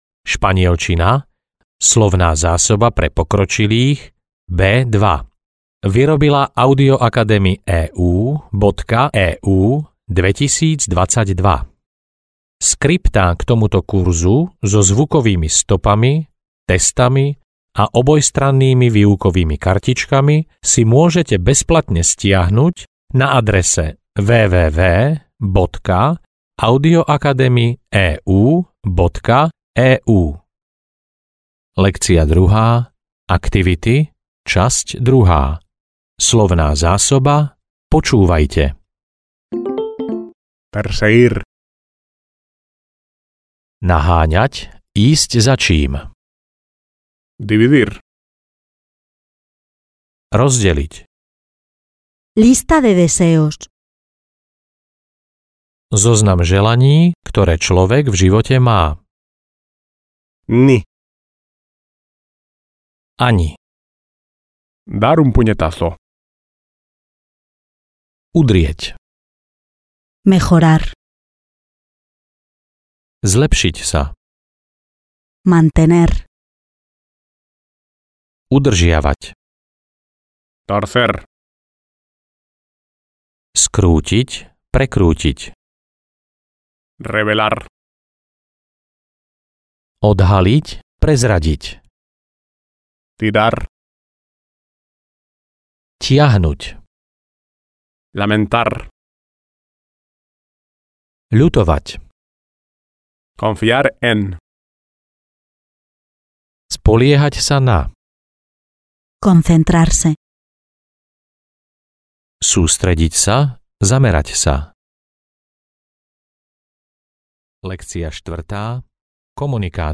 Španielčina pre pokročilých B2 audiokniha
Ukázka z knihy